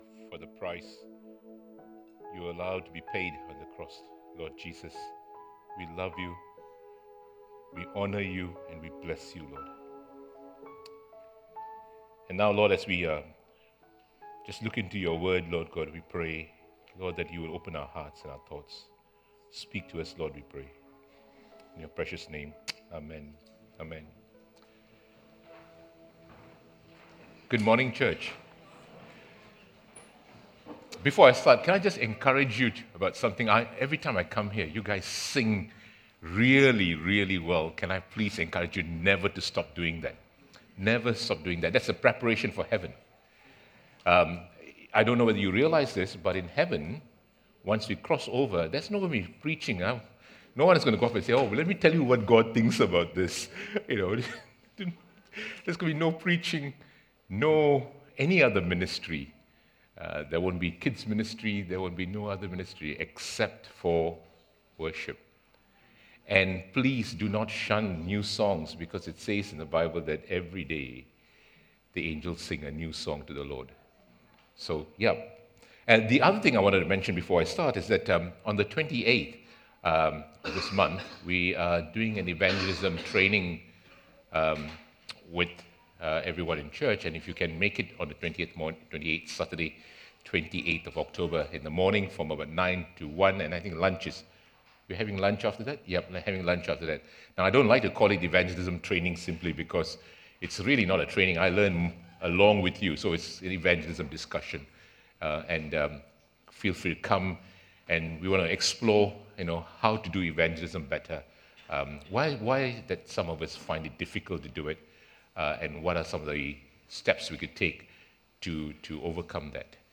Sermons | Coolbellup Community Church